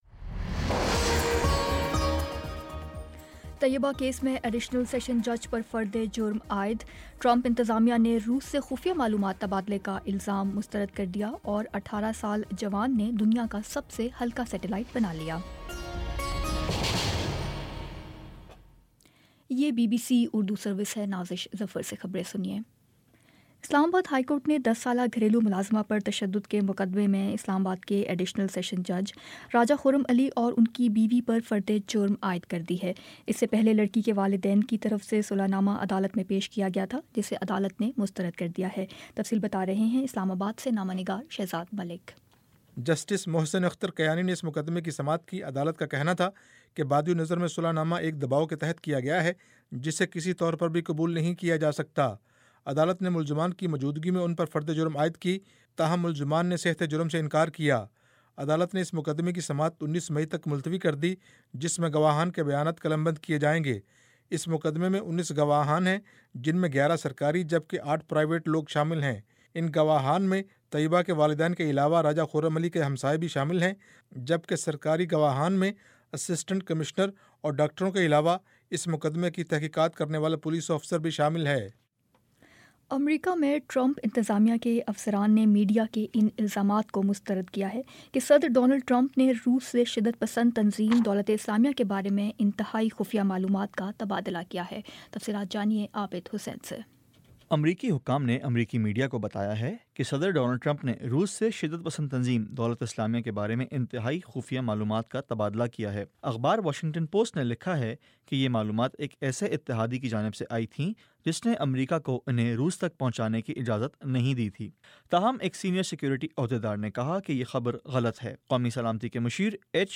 مئی 16 : شام پانچ بجے کا نیوز بُلیٹن